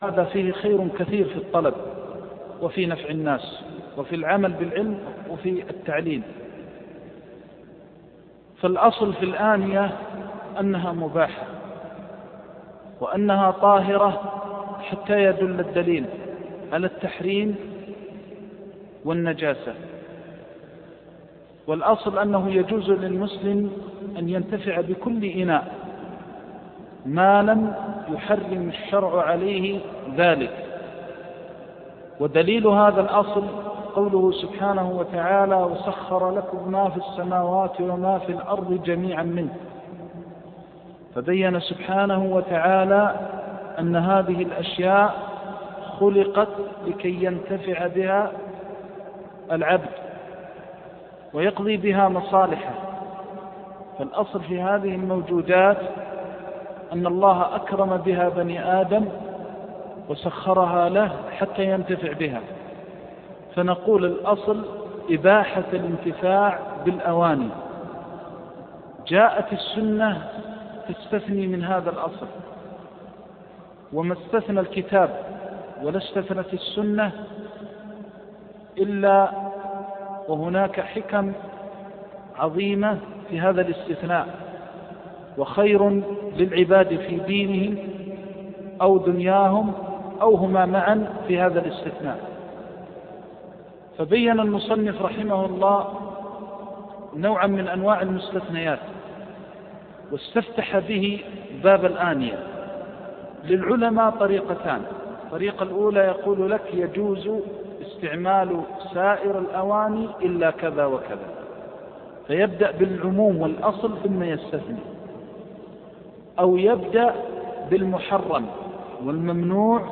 شرح كتاب الطهارة من كتاب عمدة الفقه لابن قدامة - 5 - الشيخ محمد محمد المختار الشنقيطي